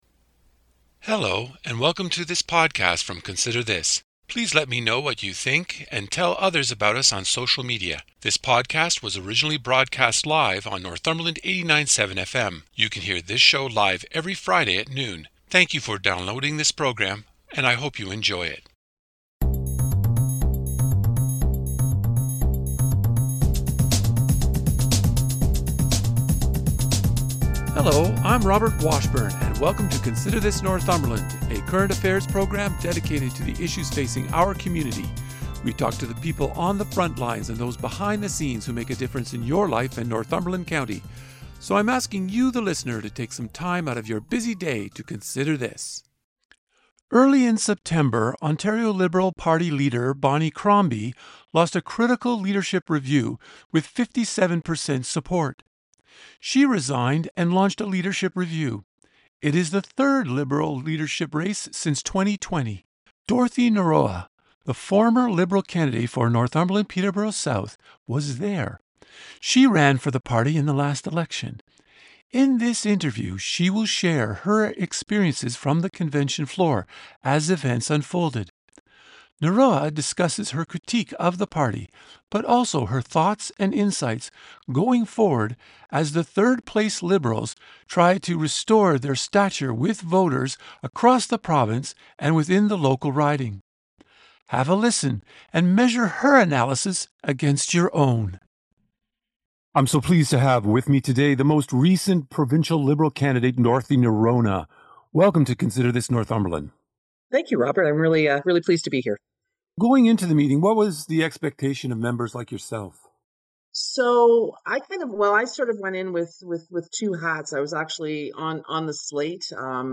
The full interview with bonus material: